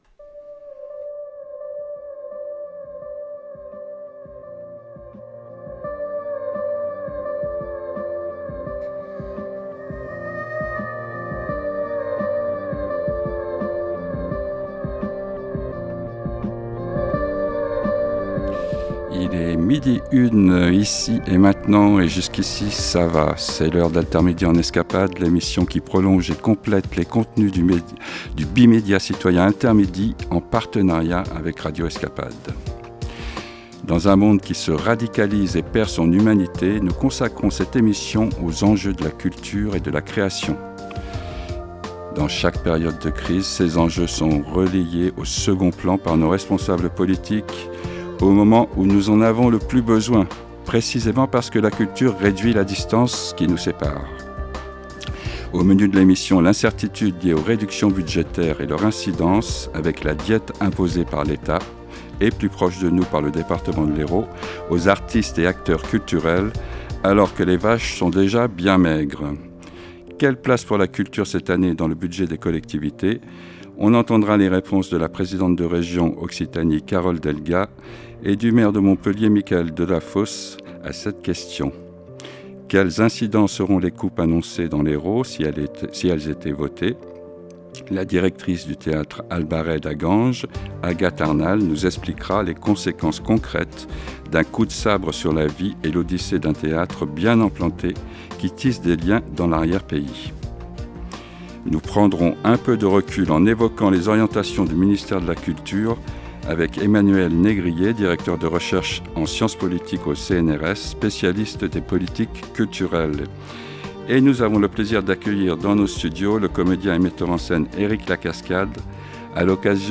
altermidi - Radio - Enjeux de la culture et de la création en période de crise
Dans un monde qui se radicalise et perd son humanité, altermidi en escapades1 consacre cette émission aux enjeux de la culture et de la création. Invité de l’émission, le comédien et metteur en scène Éric Lacascade.
Nous entendons les réponses de la présidente de région Occitanie Carole Delga et du maire de Montpellier Michaël Delafosse.